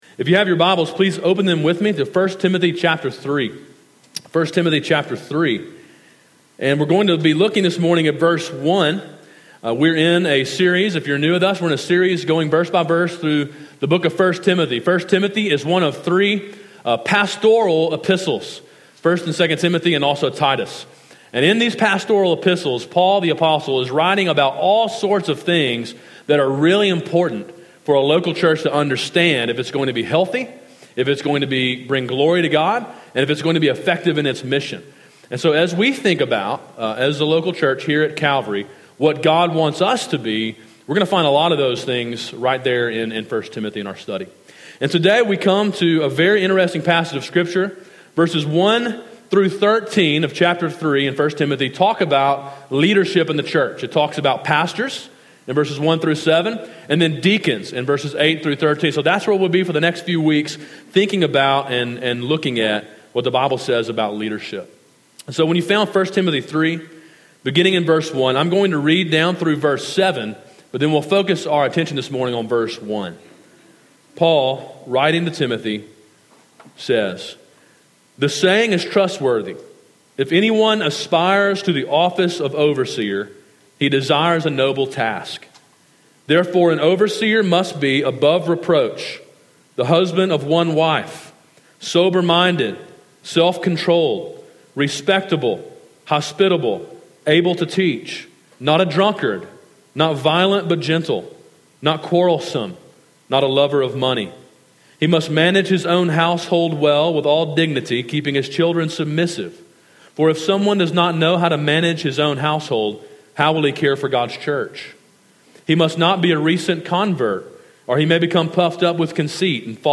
Sermon: “The Office of Overseer” (1 Timothy 3:1)
A sermon in a series on the book of 1 Timothy.